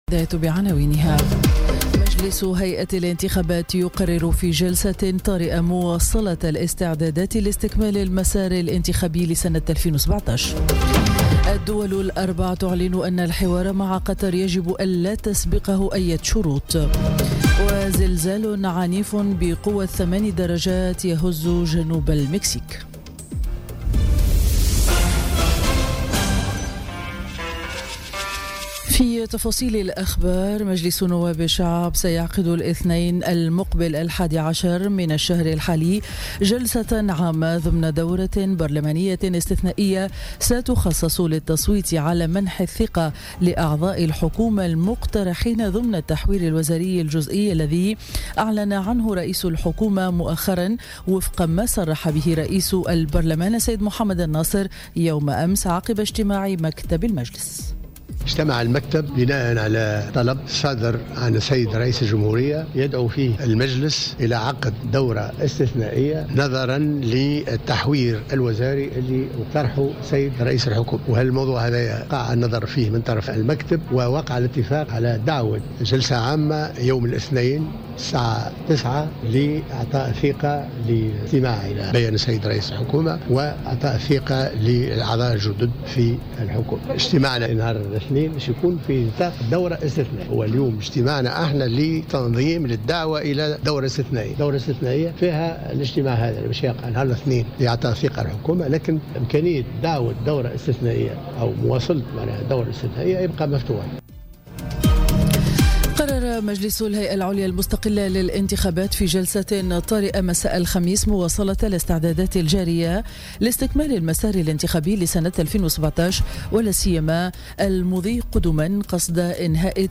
نشرة أخبار السابعة صباحا ليوم الجمعة 8 سبتمبر 2017